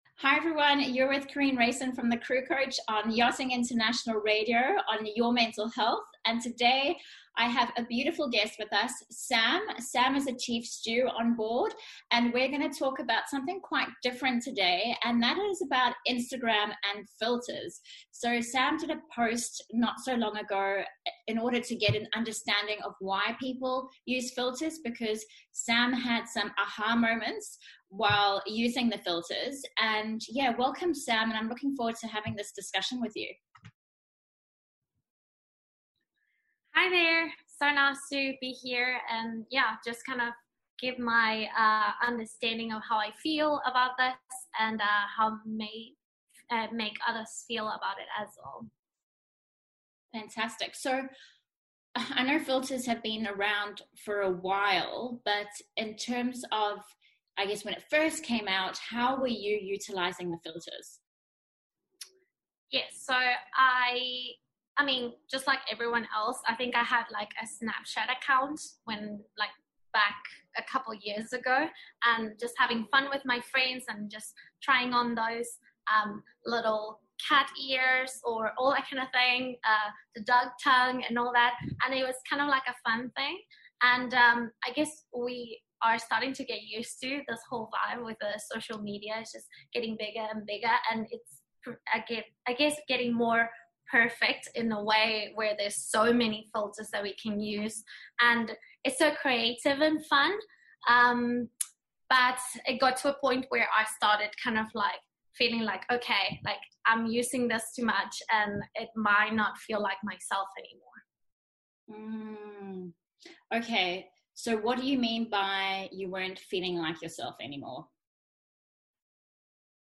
⠀⠀⠀⠀⠀⠀⠀⠀⠀ A different but interesting interview that may unveil some important reflections about your identity and perhaps start to encourage you to love parts of yourself that you may have not appreciated before.